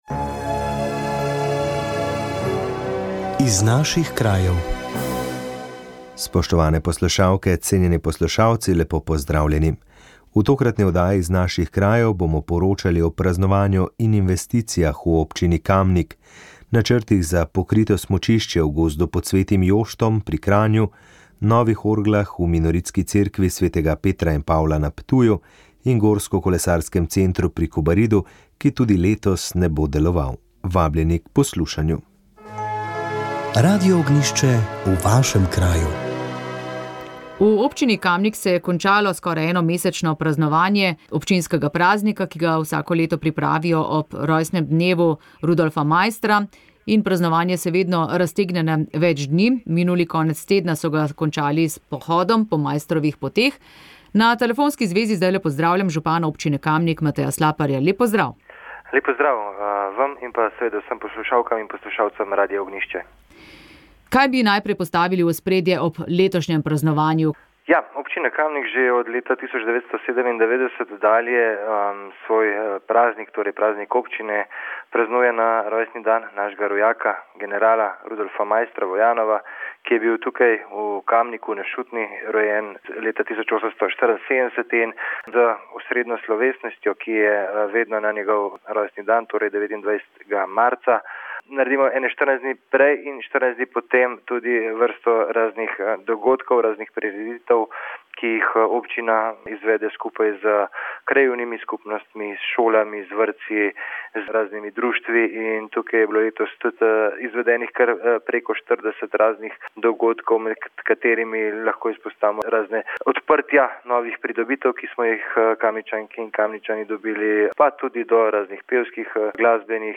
gostja je bila etnologinja in antropologinja